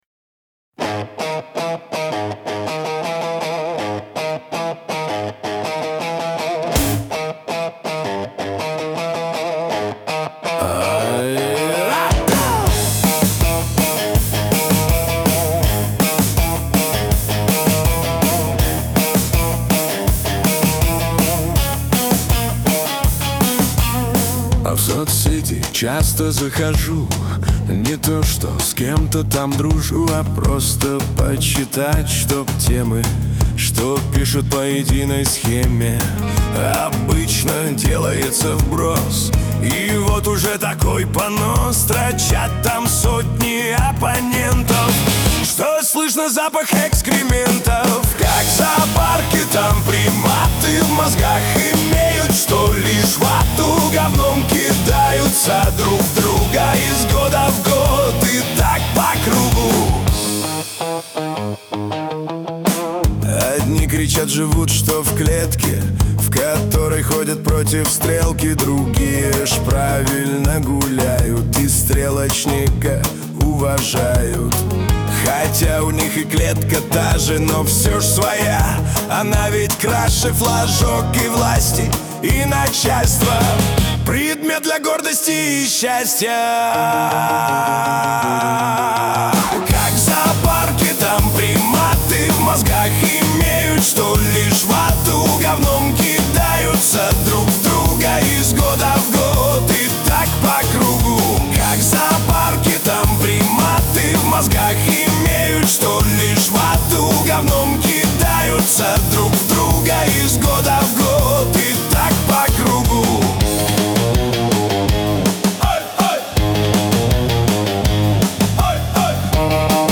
Музыкальный хостинг: /Рок